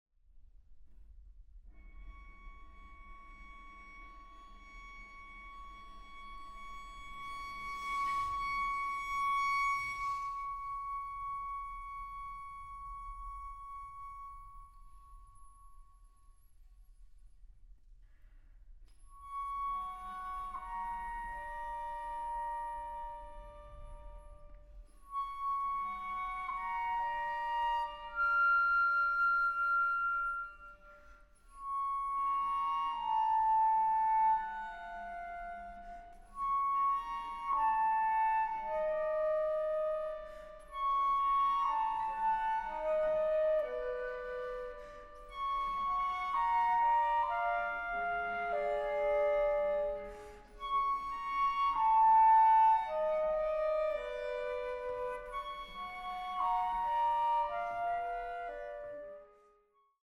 Flöte
Violoncello
Harfe